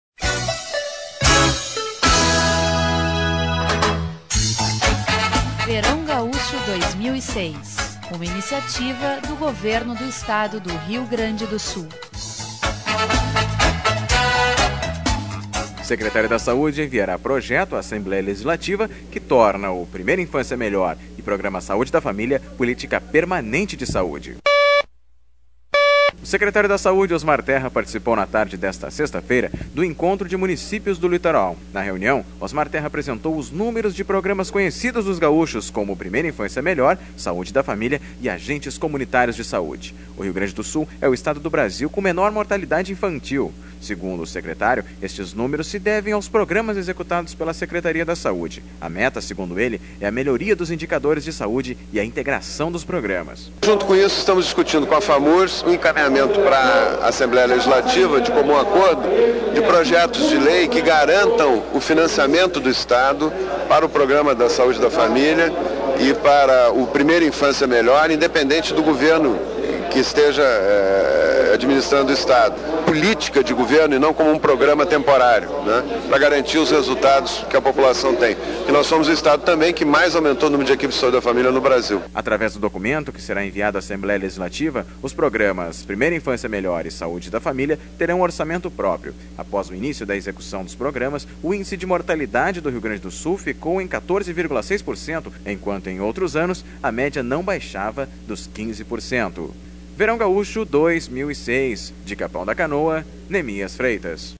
O secretário da saúde Osmar Terra, participou na tarde desta sexta-feira, do Encontro de Municípios do litoral Norte. Na reunião, Osmar Terra apresentou os números de programas conhecidos dos gaúchos... (sonora: secretário da saúde, Osmar Terra)Local: